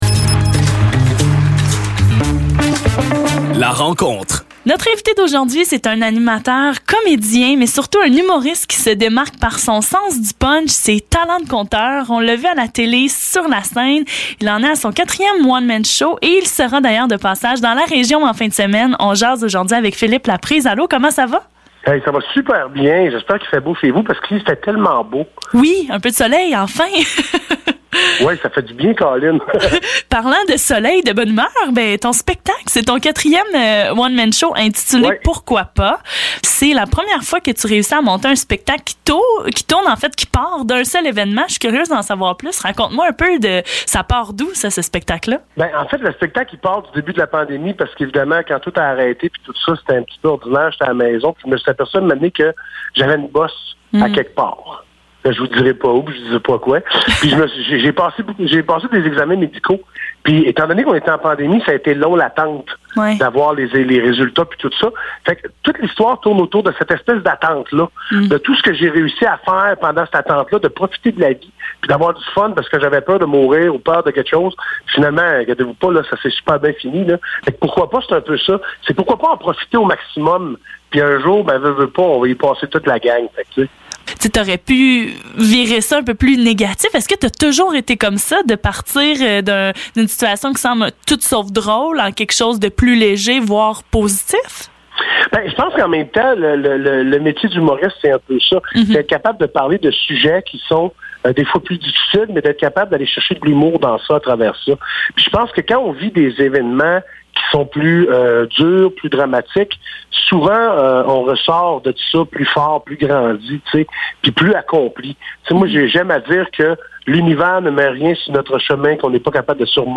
Entrevue avec l’humoriste Philippe Laprise